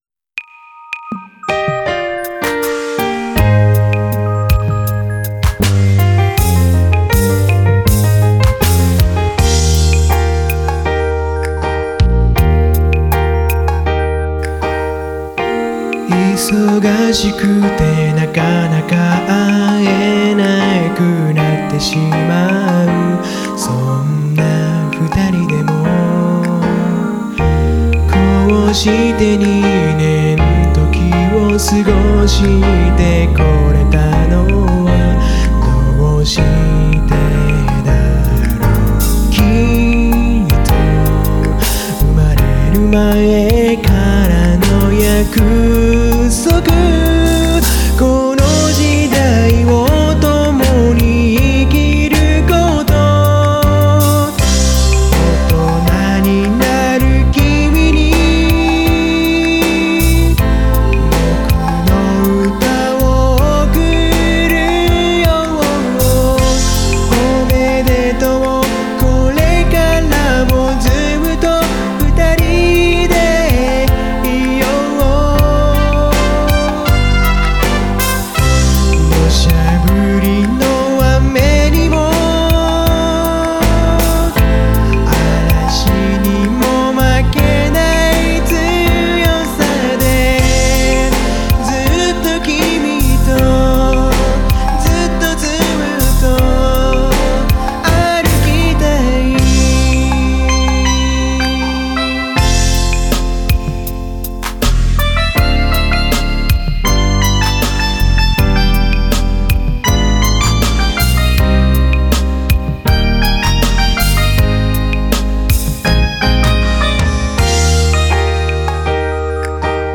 作詞・作曲・編曲・歌：坂本総合研究所
・曲の内容としては、本当にベタなラブソング
・サビの部分だけ転調してキーを上げている
音源：KORG X3
MTR：KORG D8